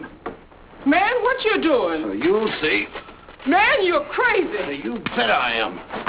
Hear audio of Victor from "My Gal Sal" and view handsome Victor in some scenes from this classic movie.